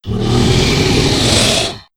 c_hakkar_hit3.wav